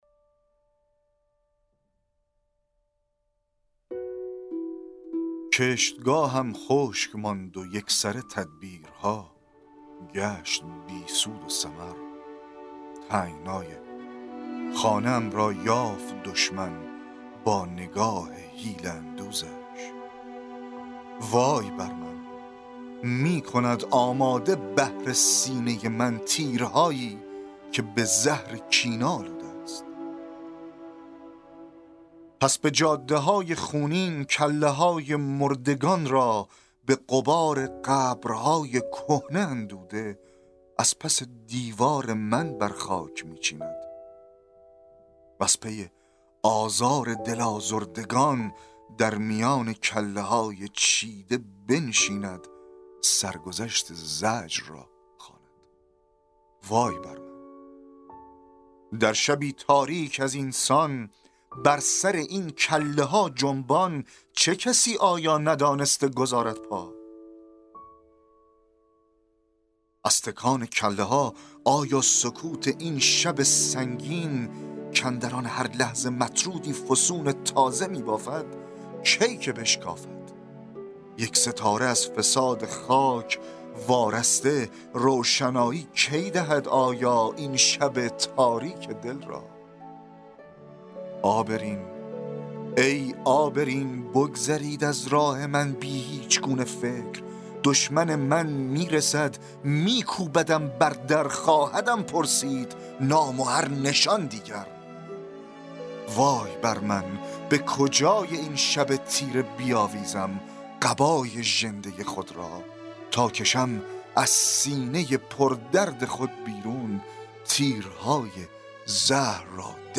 دکلمه شعر وای بر من